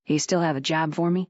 Add Juni Mission Voice Files